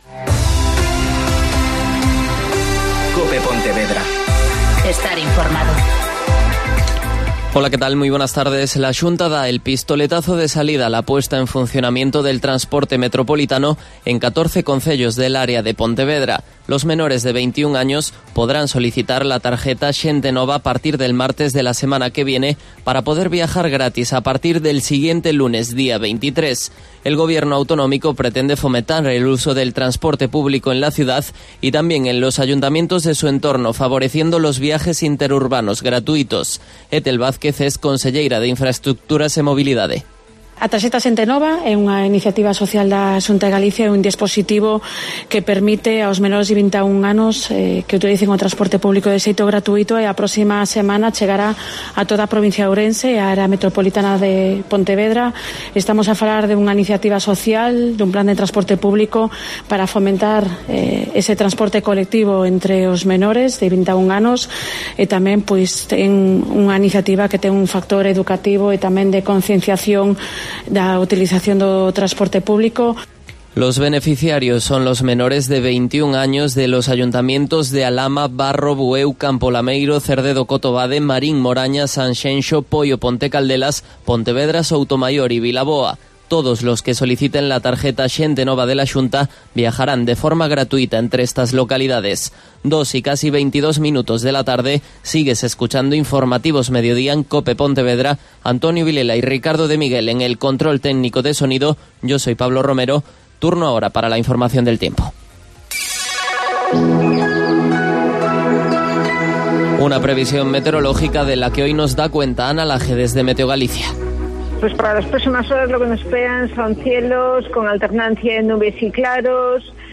Mediodía COPE Pontevedra (Informativo 14:20)